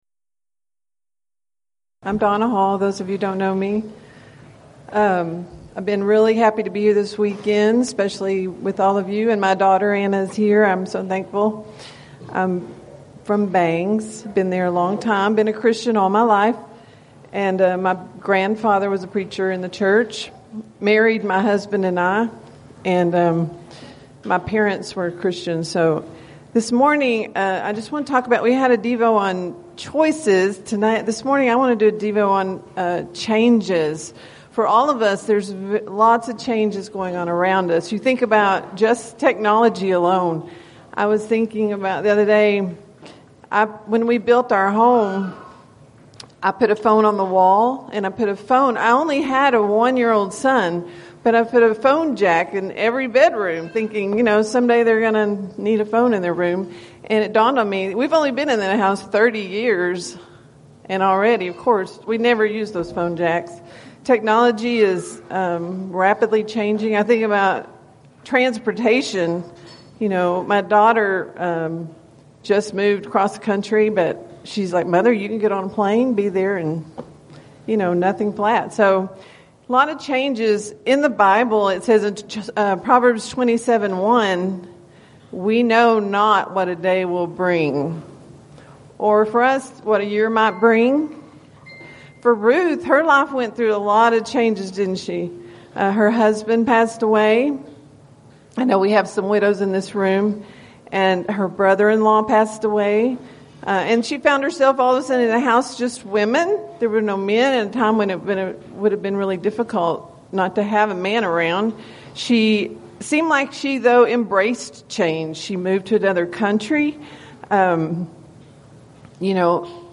Title: Devotional
Event: 7th Annual Texas Ladies in Christ Retreat